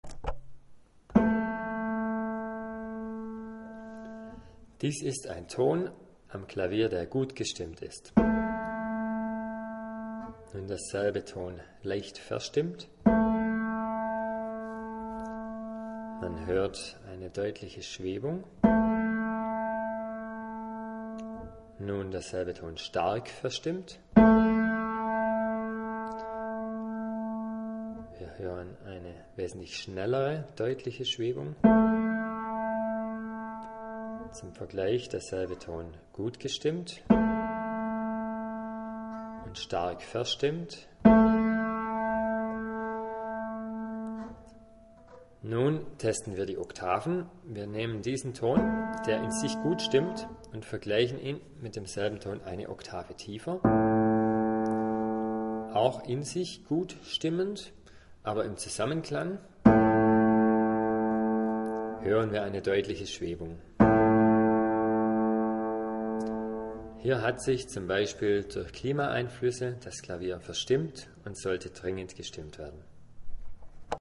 Schlagen Sie eine Taste Ihres Klavieres an: hören Sie im Ton eine Schwebung (ein Vibrato, eine rhythmische Veränderung des Tones im Zeitverlauf)? Hört er sich unruhig, vibrierend an? Dann ist dieser Ton in sich verstimmt.
Ein gut gestimmter Ton „steht“, er ist klar und ruhig.
Vibriert der Klang, zeigt das eine Verstimmung z.B. durch Luftfeuchteschwankungen an.
stimmt-mein-klavier.mp3